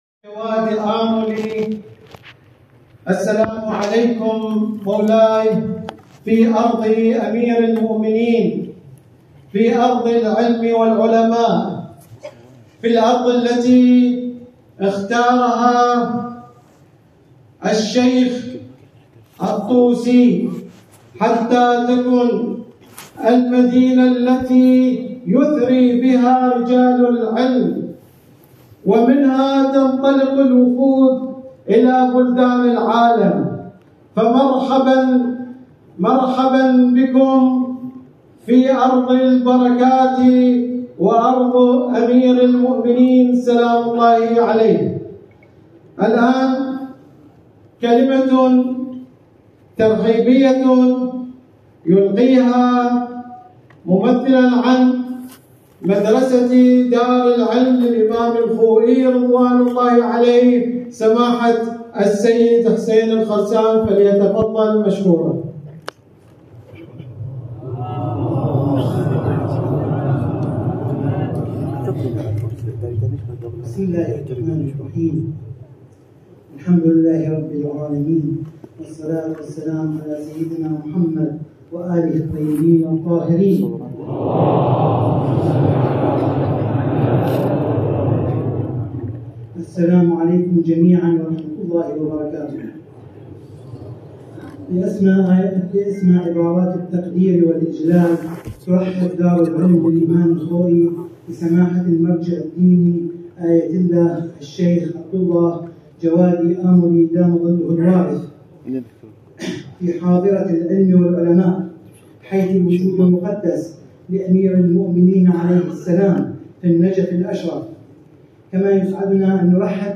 صوت | سخنان آیت‌الله جوادی آملی در جمع اساتید و طلاب حوزه علمیه نجف
به گزارش خبرگزاری بین‌المللی اهل‌بیت(ع) ـ ابنا ـ جمع گسترده ای از اساتید و طلاب حوزه علمیه نجف اشرف در دارالعلم(مدرسه مرحوم آیت الله العظمی خوئی در نجف اشرف) با آیت الله العظمی جوادی آملی از مراجع تقلید جهان تشیع، دیدار کردند.